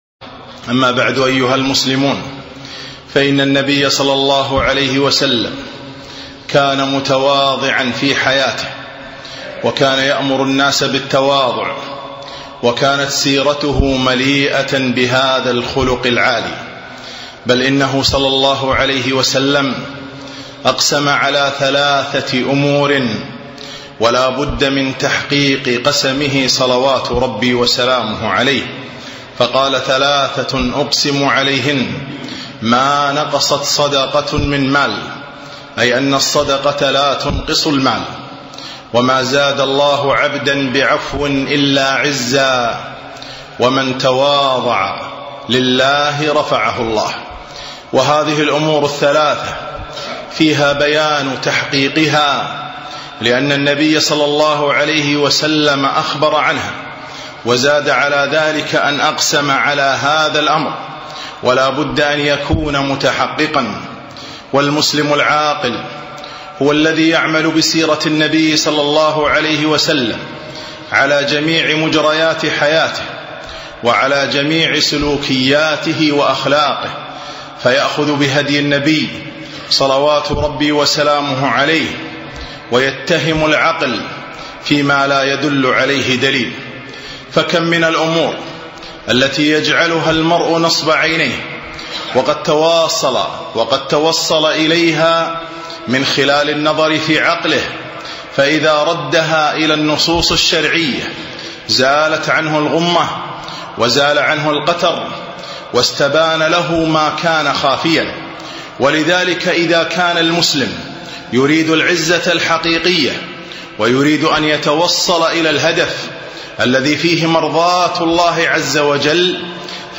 خطبة - تواضع النبي صلى الله عليه وسلم